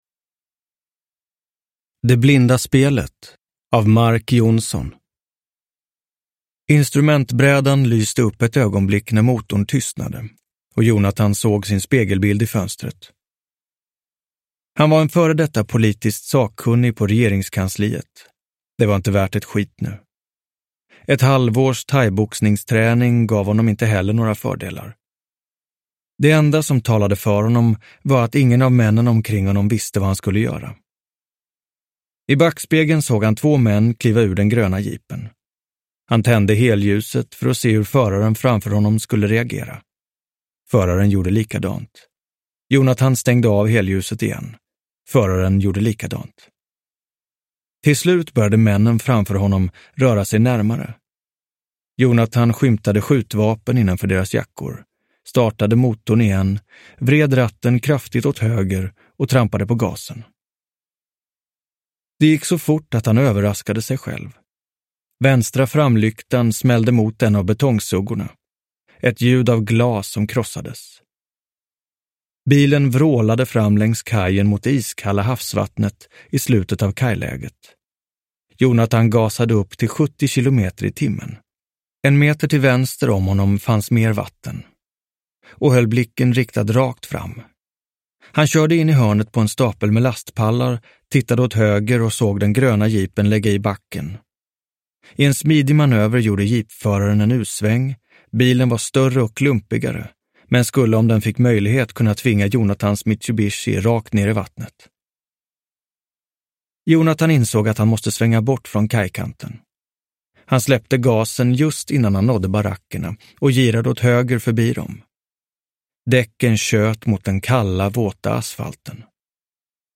Det blinda spelet – Ljudbok – Laddas ner
Uppläsare: Martin Wallström